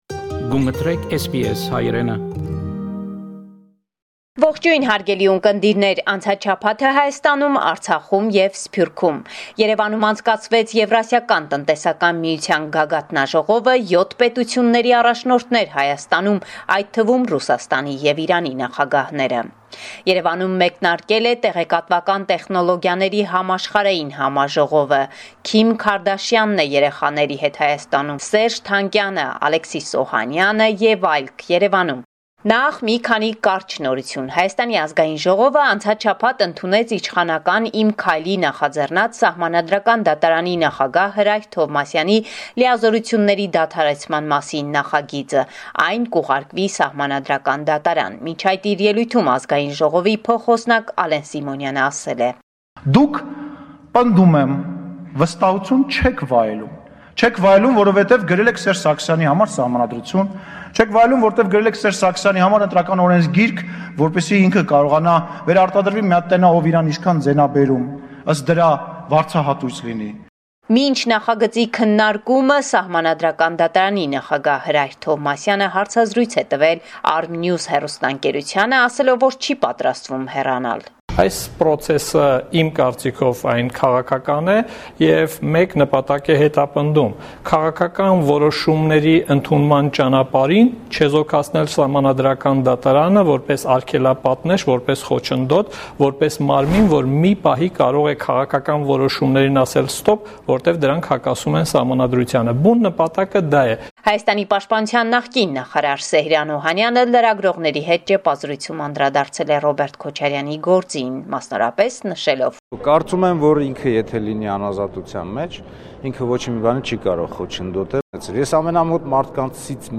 Latest News from Armenia– October 29, 2019